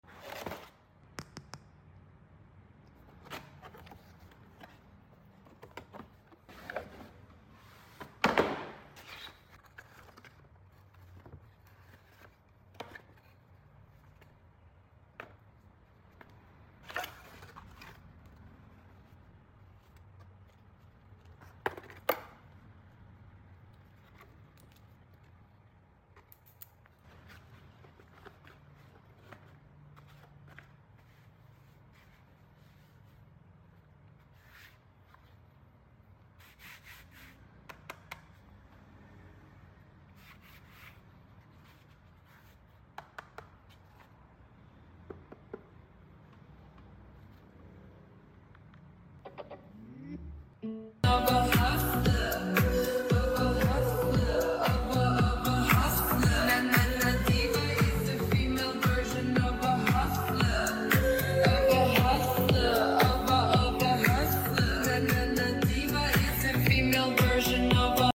UNBOXING & SOUND TEST: JBL sound effects free download